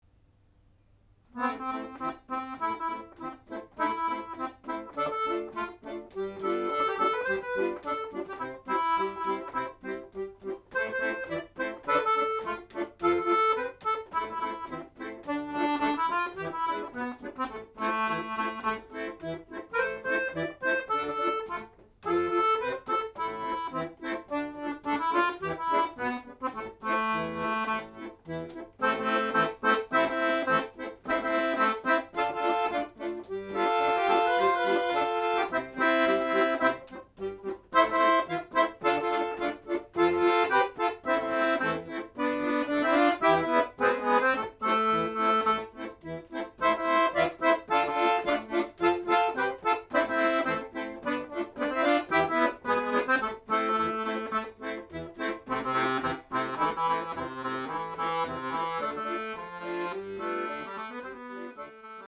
accordeonAccordeon